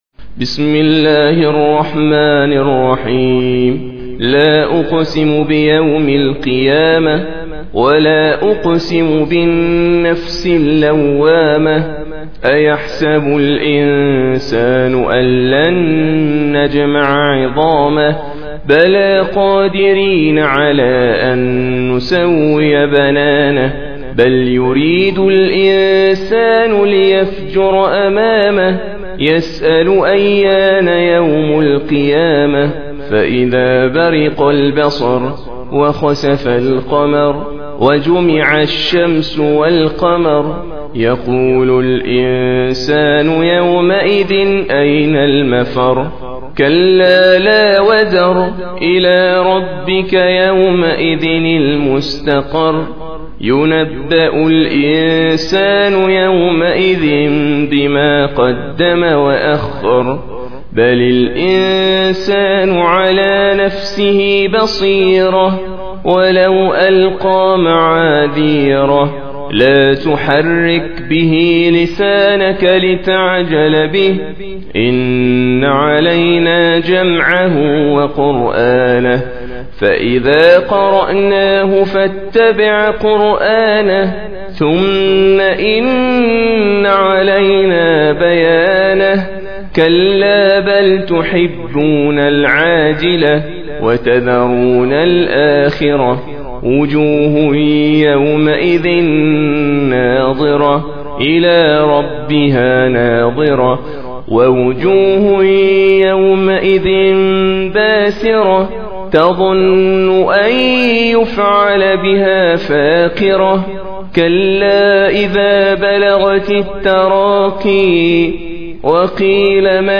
Surah Sequence تتابع السورة Download Surah حمّل السورة Reciting Murattalah Audio for 75. Surah Al-Qiy�mah سورة القيامة N.B *Surah Includes Al-Basmalah Reciters Sequents تتابع التلاوات Reciters Repeats تكرار التلاوات